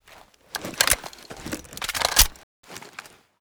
5caee9fba5 Divergent / mods / Tommy Gun Drop / gamedata / sounds / weapons / thompson / 1921_new_reload.ogg 112 KiB (Stored with Git LFS) Raw History
1921_new_reload.ogg